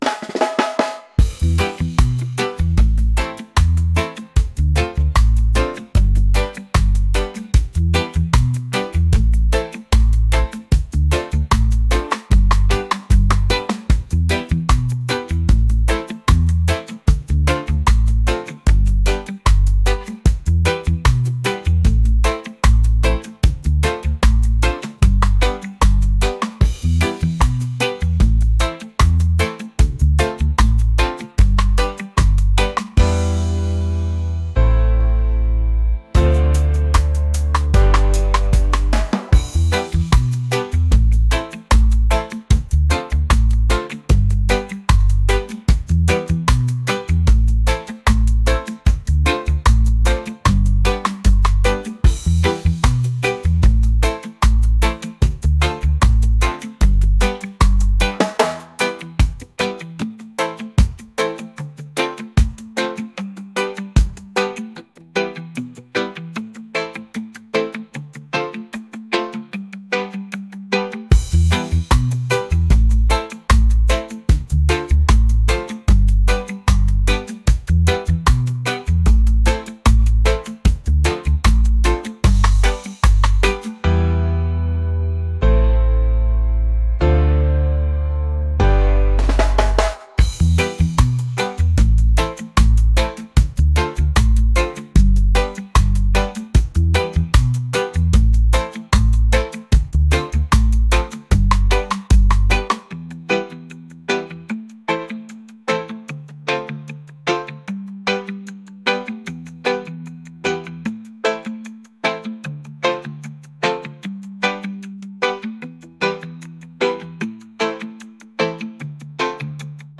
reggae | soul & rnb | lounge